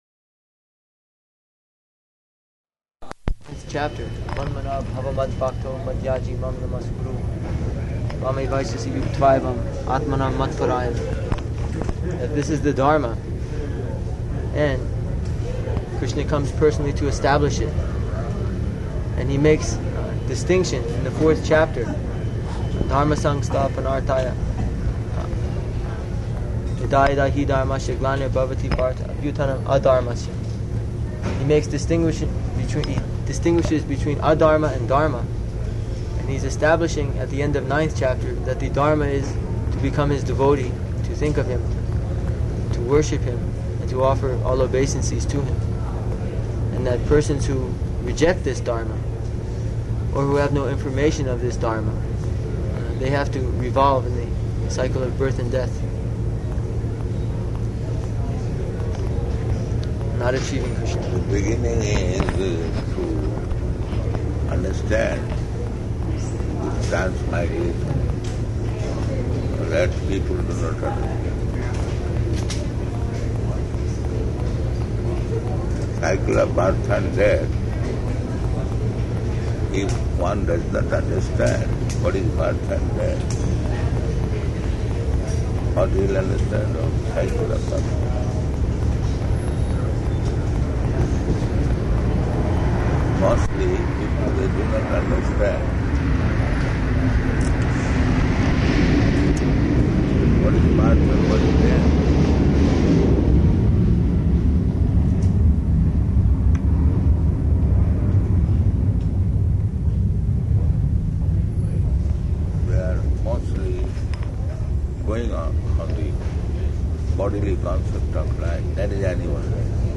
Conversation in Airport and Car to New Vrindavan
Type: Conversation
[at airport; noise of airport announcements throughout]